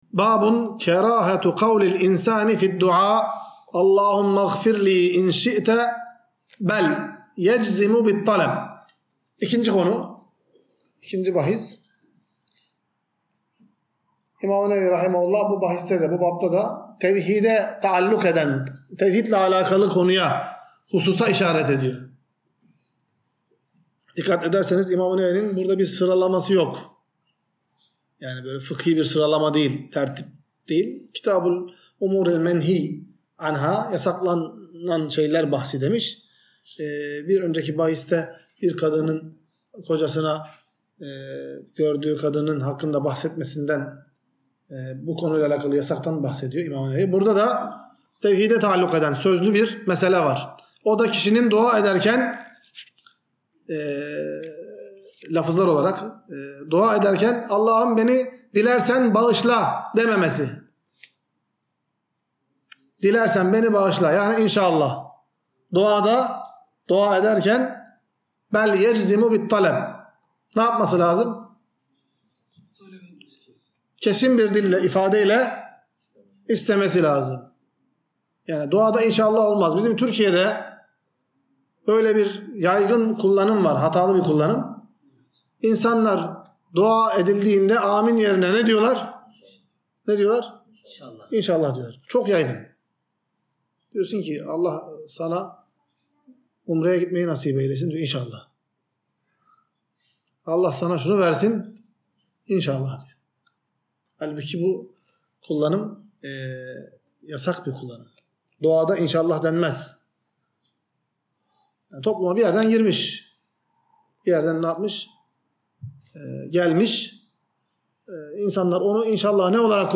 Ders - 79. Bölüm | İnsanın Dua Ederken" Allah'ım Dilerse Beni Bağışla" Demesinin Mekruh Olduğu, Aksine Israrla İstemesinin Gerektiği